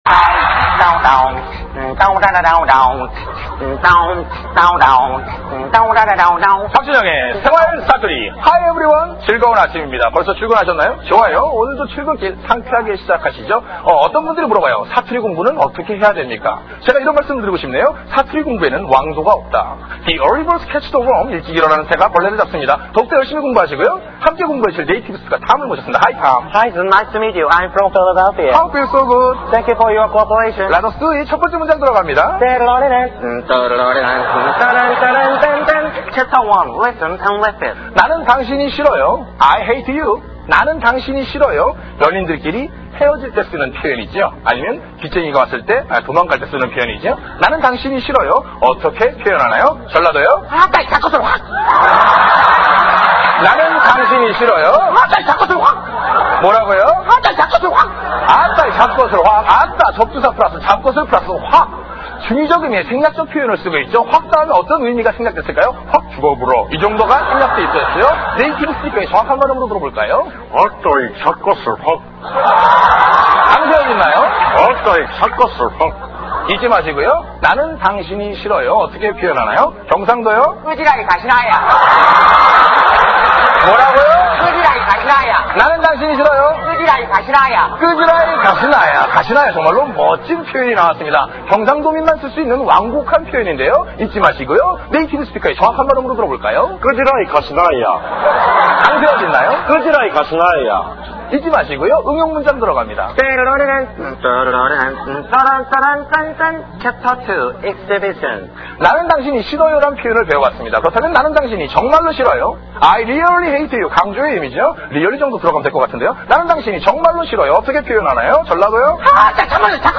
[318] 생활 사투리 2, 3, 4편들... ^^
생활사투리3.mp3